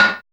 MACHINERY.wav